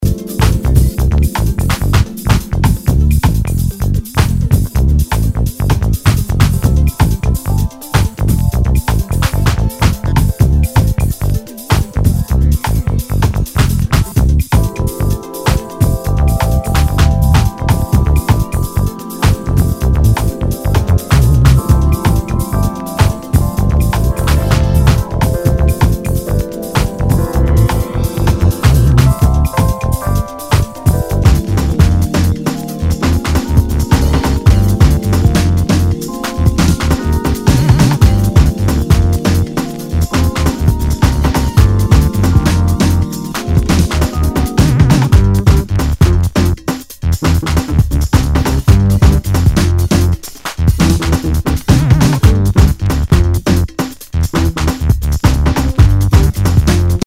Nu- Jazz/BREAK BEATS
全体にチリノイズが入ります。